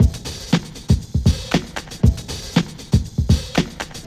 WHAT'S GOOD Drum Break (117.9bpm).wav